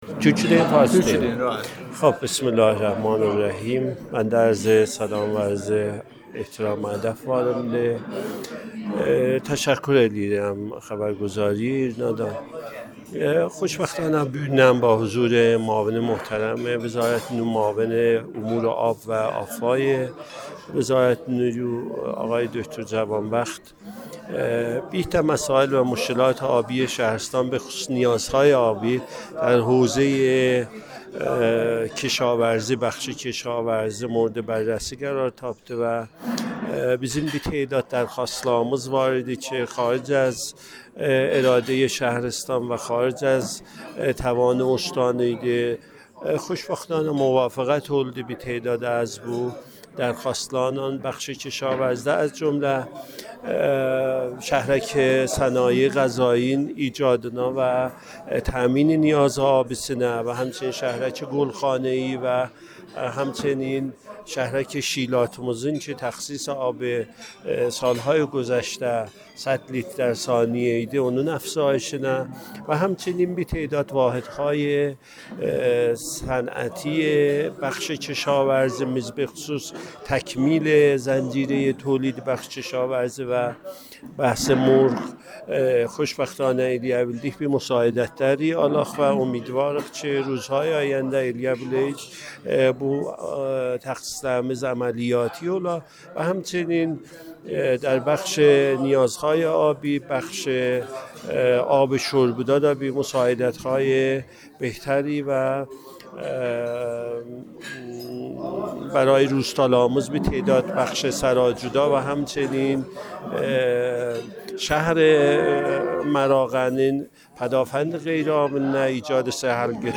بررسی نیازهای حوزه آب و فاضلاب مراغه و عجبشیر با حضور معاون وزیر نیرو پادکست گفت‌وگوی خبرنگار ایرنا با فرماندار مراغه در حاشیه جلسه بررسی نیازهای حوزه آب و فاضلاب